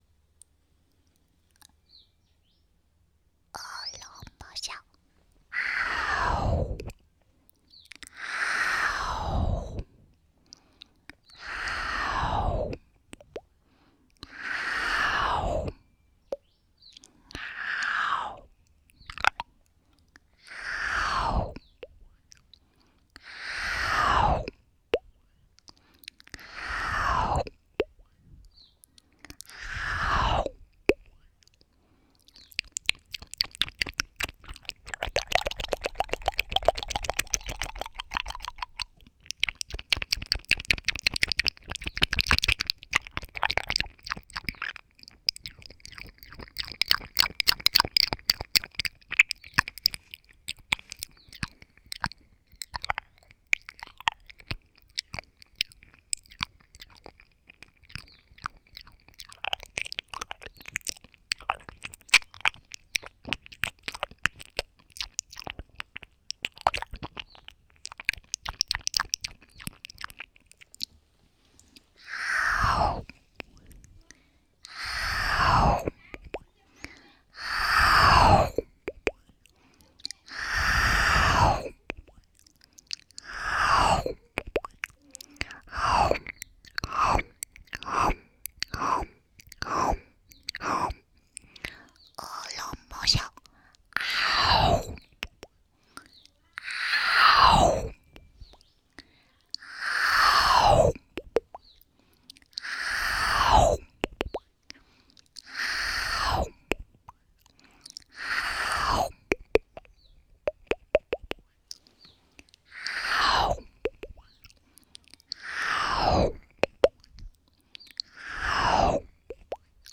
恶龙咆哮口腔音